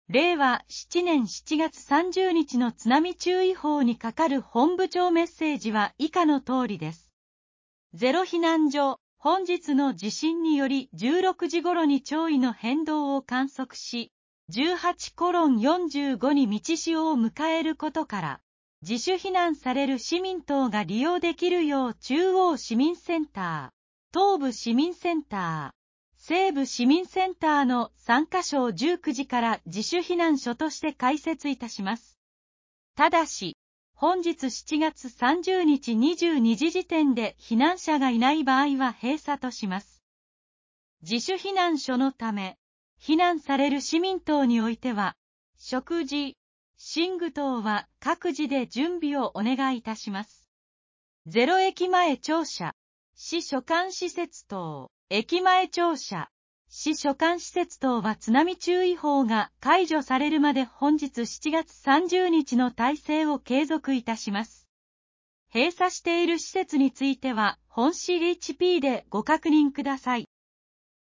令和７年７月３０日の津波注意報にかかる本部長メッセージ | 青森市防災情報